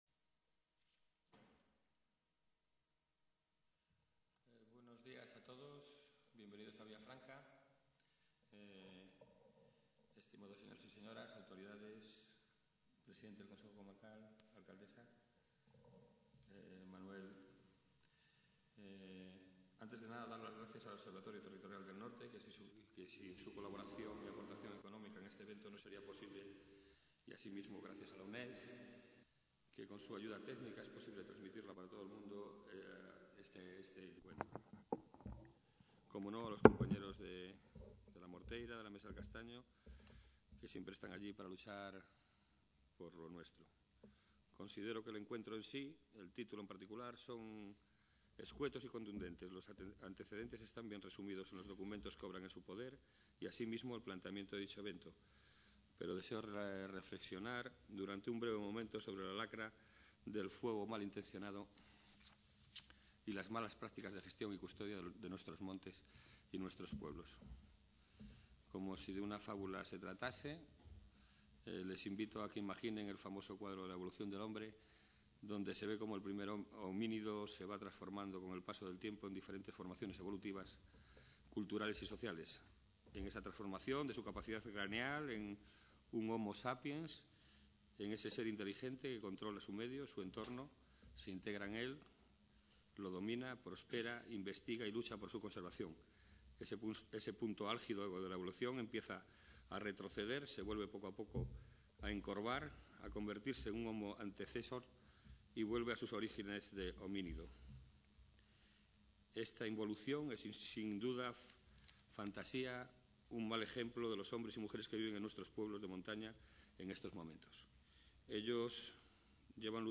Inauguración oficial
Conservación y uso del Bosque. Por un futuro sin incendios | Red: UNED | Centro: UNED | Asig: Reunion, debate, coloquio...